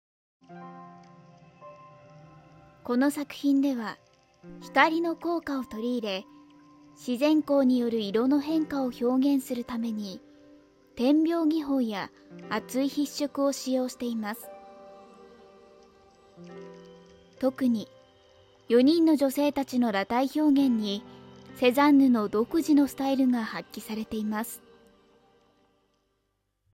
丁寧・高品質・リーズナブルなプロの女性ナレーターによるナレーション収録
アプリPR